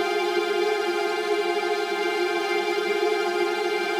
Index of /musicradar/gangster-sting-samples/Chord Loops
GS_TremString-Fmin9.wav